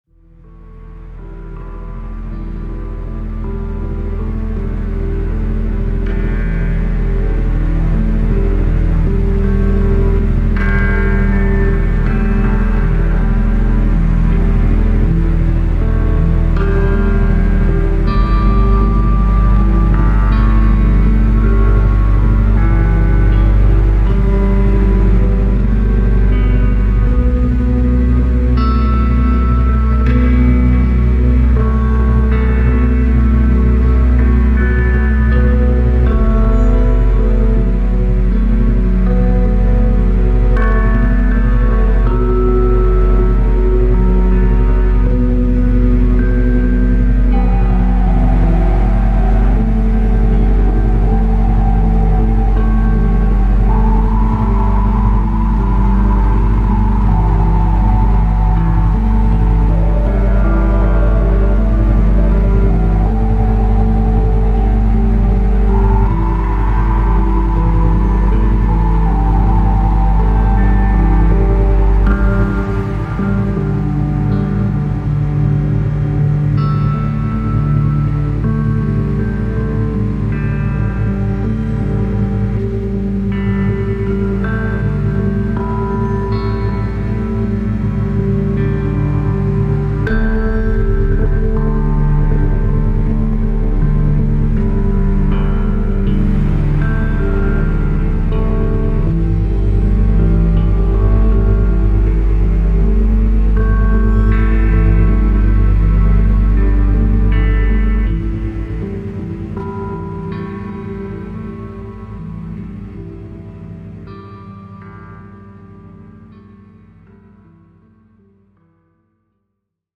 Der QUICK-SLEEPER beinhaltet eine Zusammenstellung von Isochronen Tönen und Binauralen Beats.